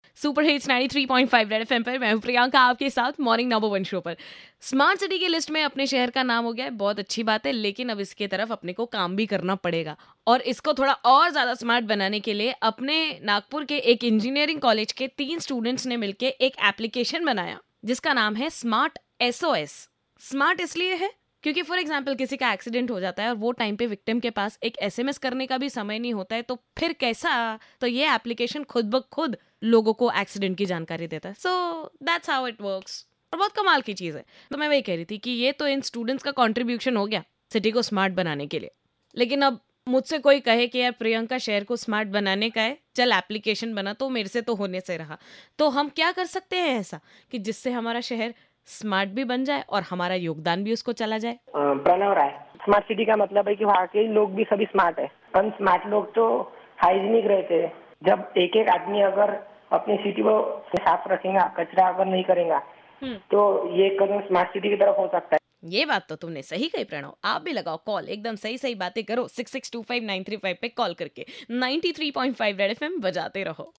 talking to caller about smart city